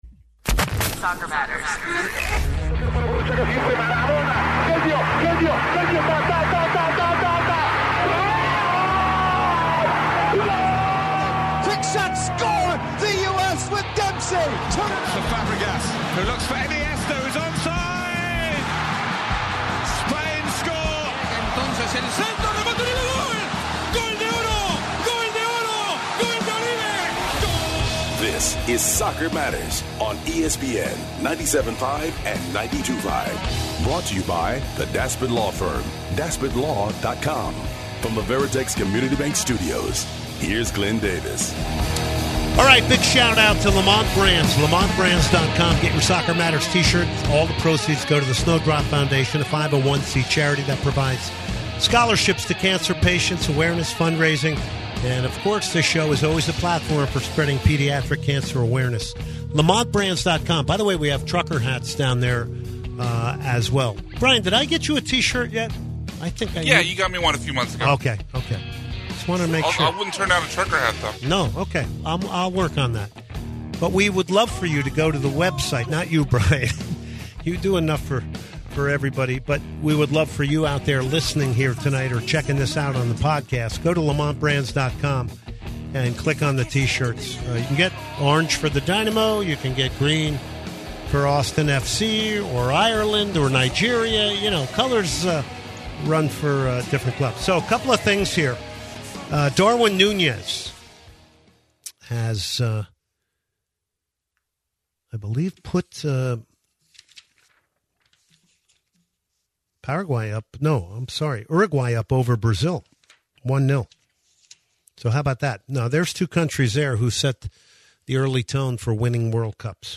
plus interviews with Houston Dynamo head coach Ben Olsen